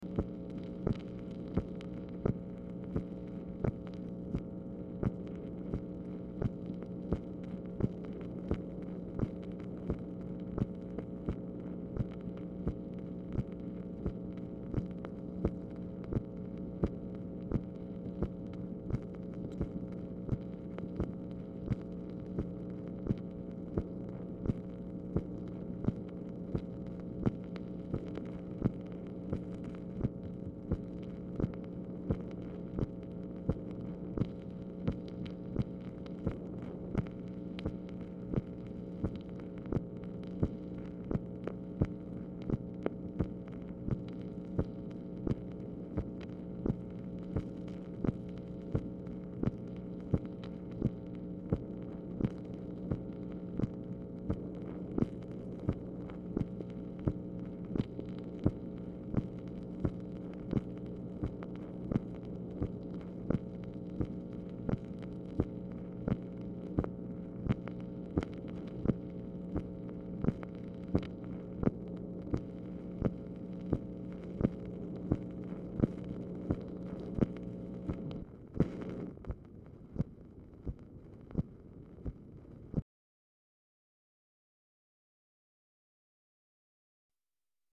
Telephone conversation # 6841, sound recording, MACHINE NOISE, 2/15/1965, time unknown | Discover LBJ
Format Dictation belt
White House Telephone Recordings and Transcripts Speaker 2 MACHINE NOISE